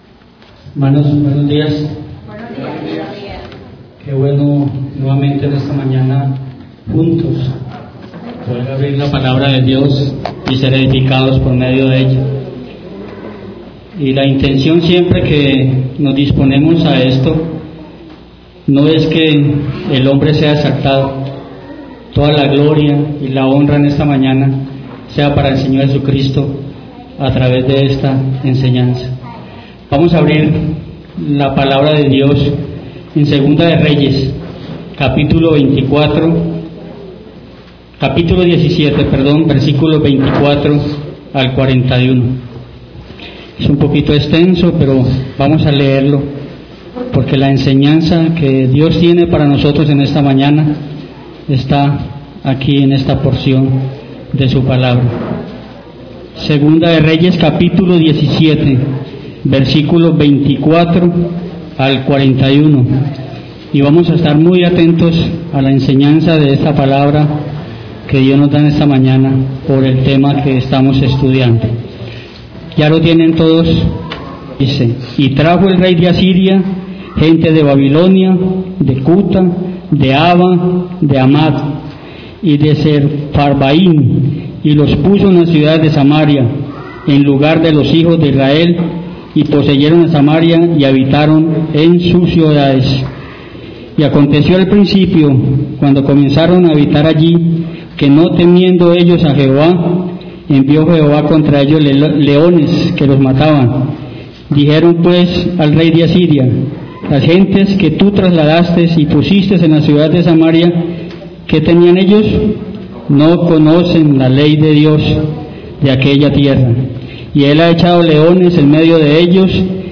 Una serie de enseñanzas que tratan sobre el importantísimo tema de la Adoración.